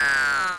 synth8.wav